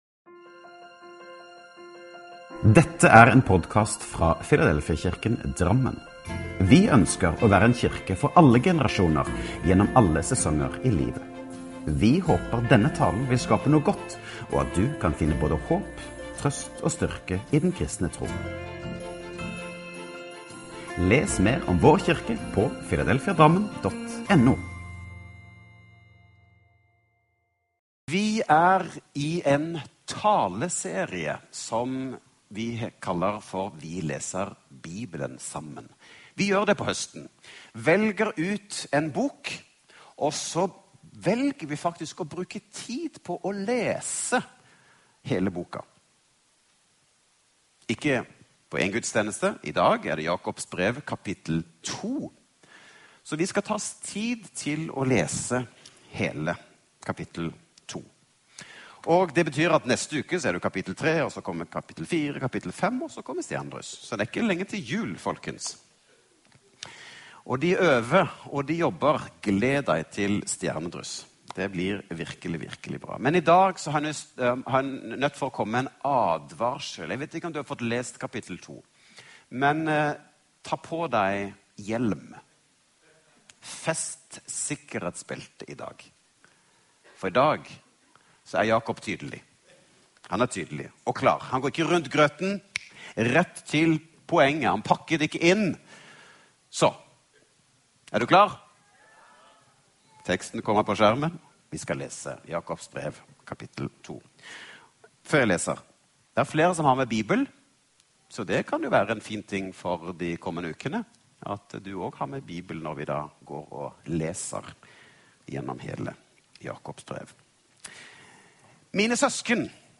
Last ned talen til egen maskin eller spill den av direkte: